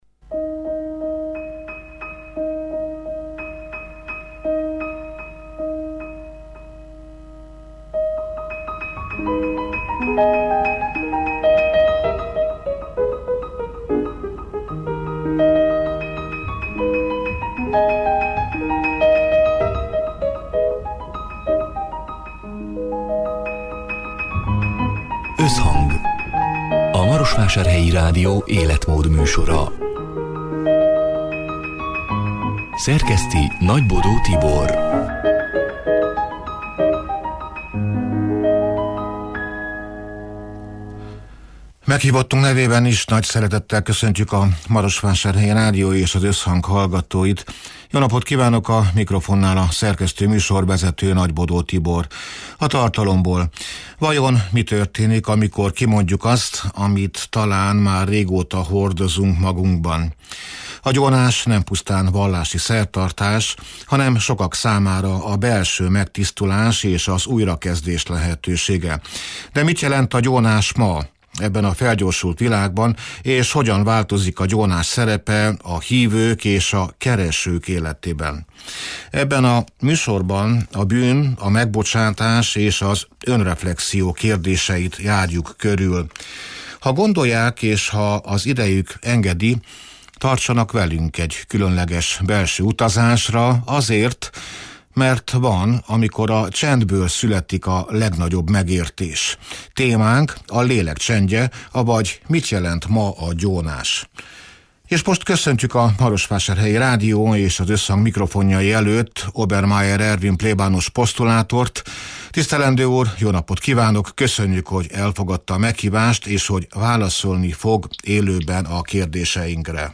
(elhangzott: 2025. június 25-én, szerdán délután hat órától élőben)